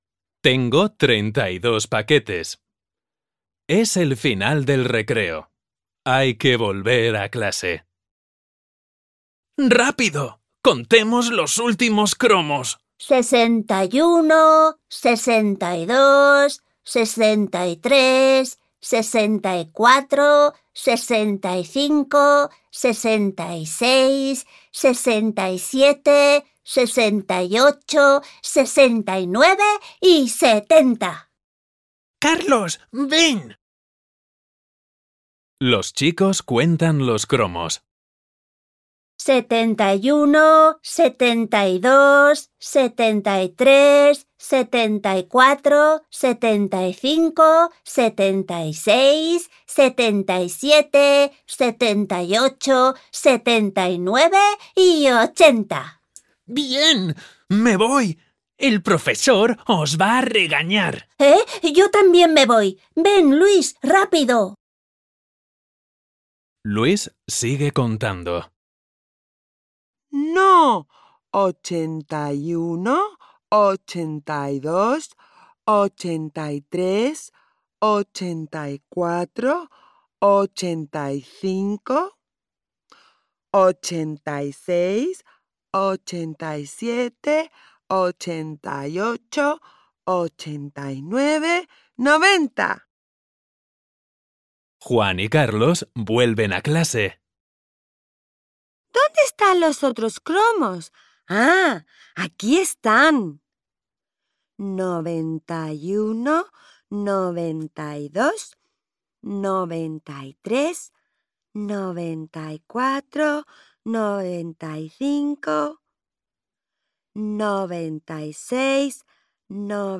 To help non-specialist teachers, model good pronunciation and provide valuable practice in listening skills, the book comes with free audio downloads and English translations of the stories.